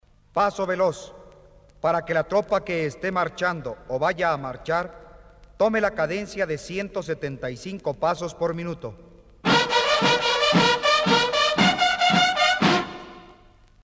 TOQUES MILITARES REGLAMENTARIOS EN MP3.